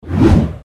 Hiệu ứng âm thanh SWOOSH #3 mp3 - Tải hiệu ứng âm thanh để edit video